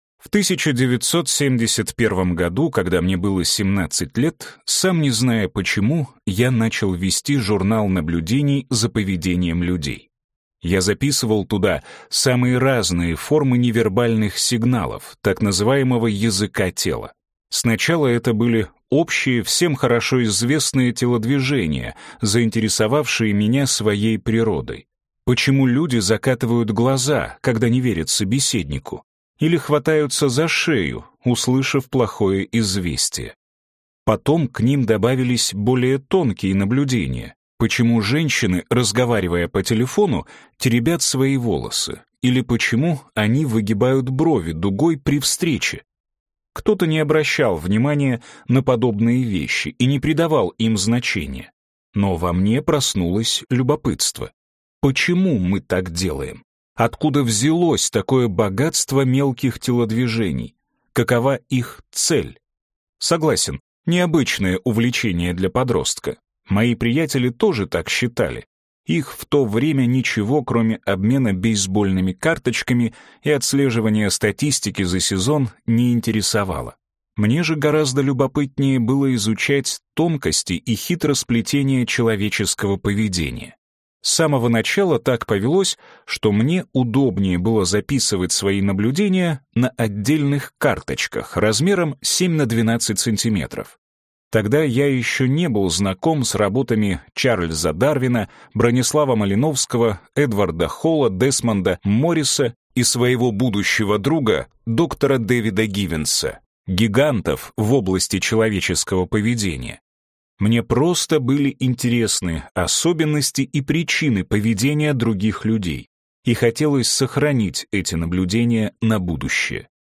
Aудиокнига Словарь языка тела